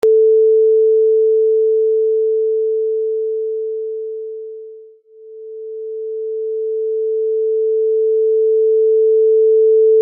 Original unprocessed signal
The original unprocessed signal is an oscillator whose gain decreases from full volume to silence linearly, then increases back to full volume linearly.